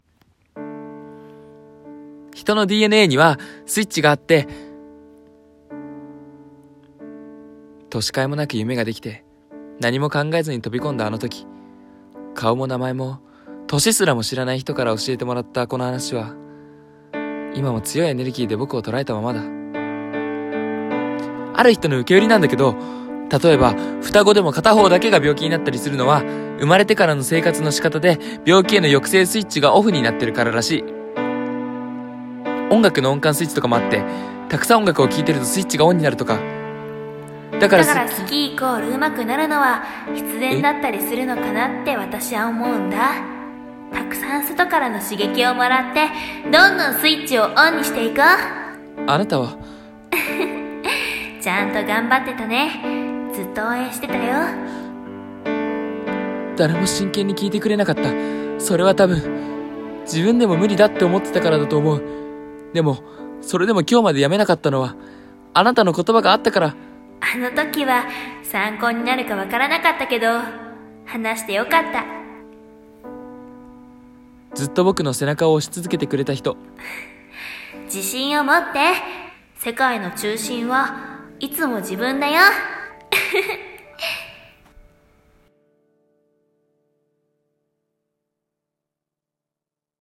【声劇】One Step Ahead